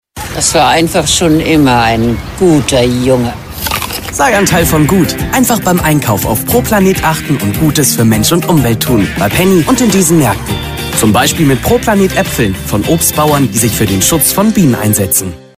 Junge & frische Werbesprecher:innen – New Voices
junger Werbesprecher mit Selbstbewusstsein
Stimmcharakter:         cool, echt, erfrischend, positiv, vielseitig, energievoll